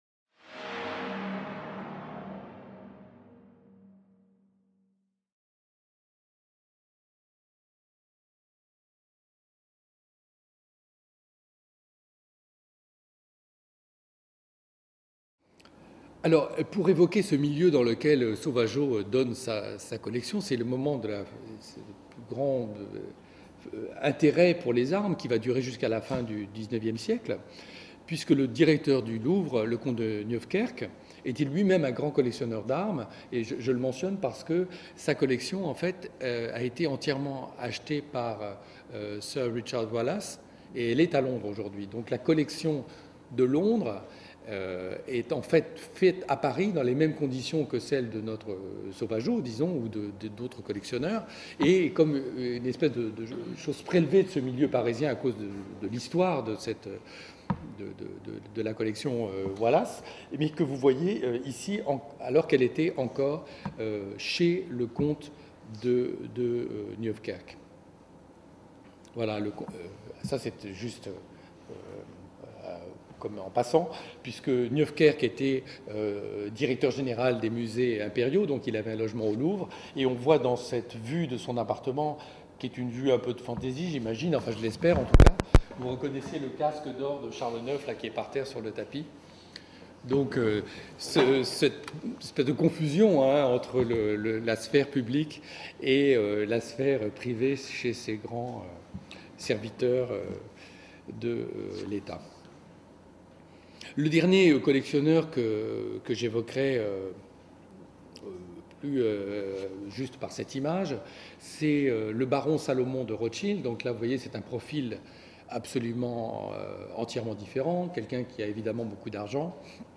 Troisième séance du séminaire "Collections" 2014-2015.